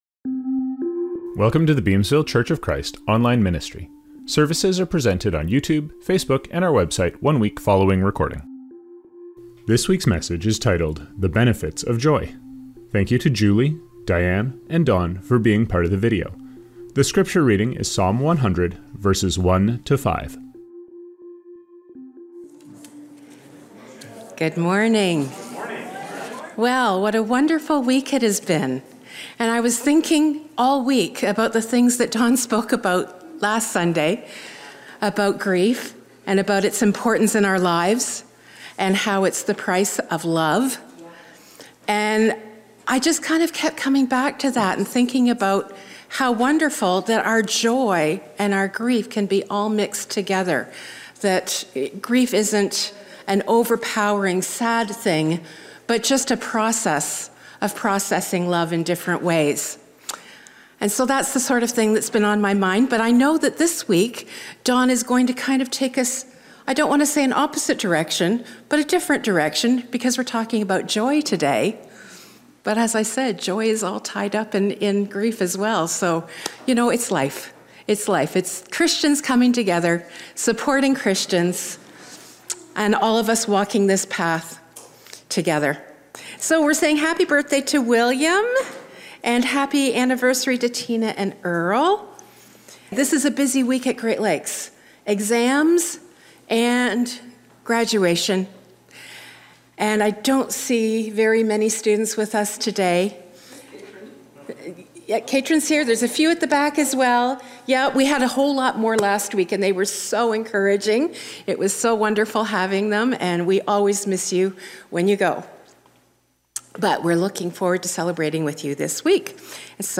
Reading – Psalm 100:1-5.